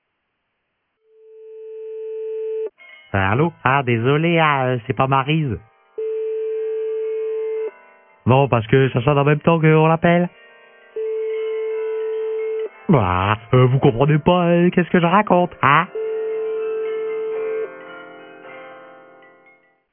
(Parodie)